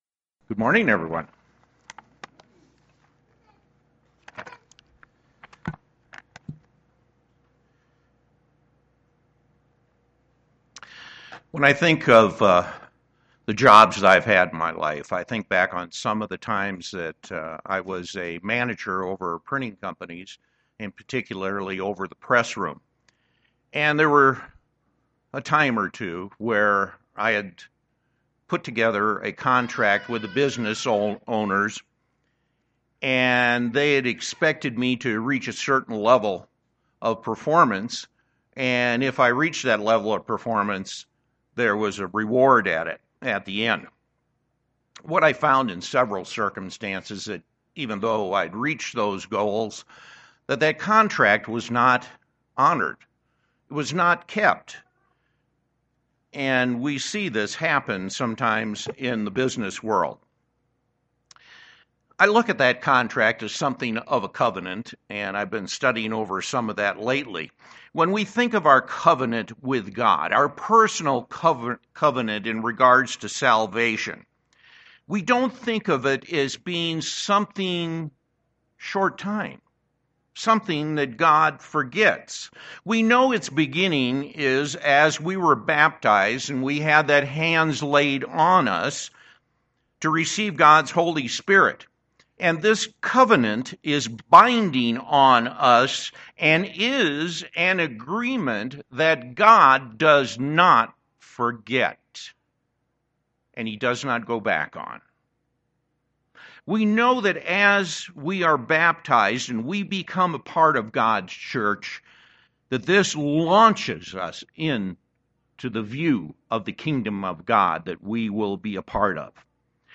Sermons
Given in Denver, CO